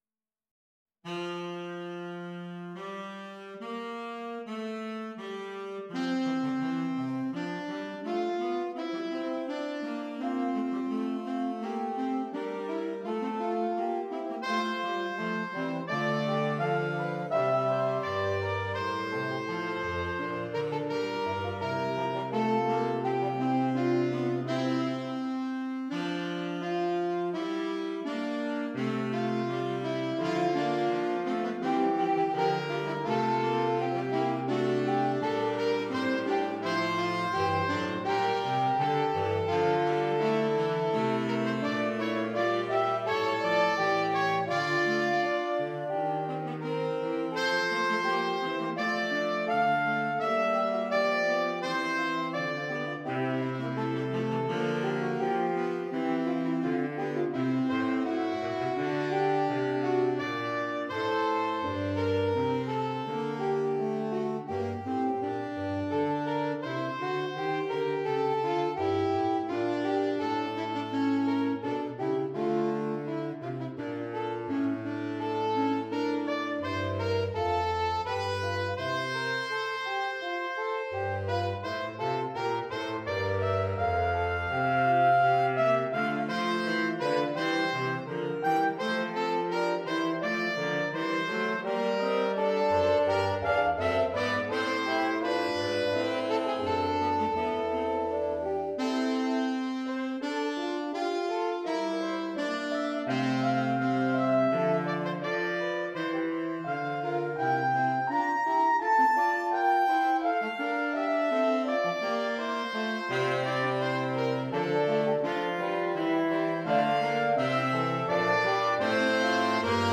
Saxophone Ensemble